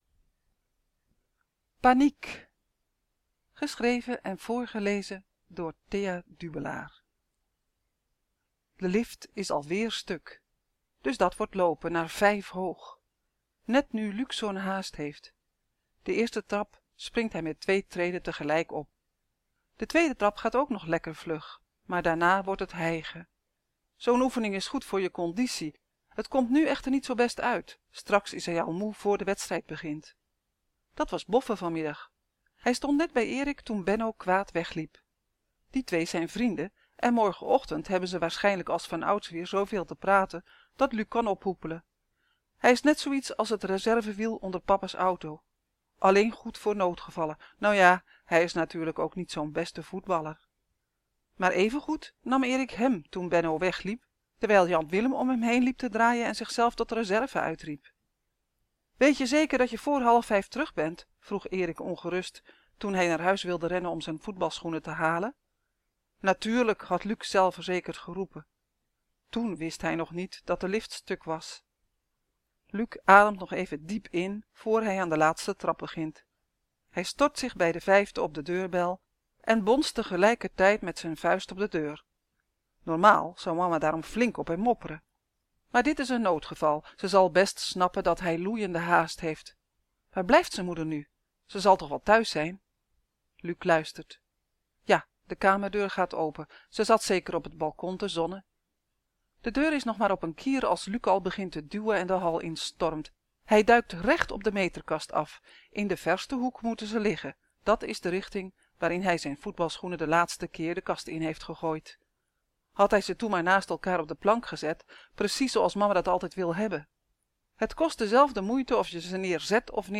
Ik lees elke dag een verhaal voor uit de bundel ‘Vliegeren’. Vandaag een verhaal over voetbalschoenen en een thuis dat verdwijnt…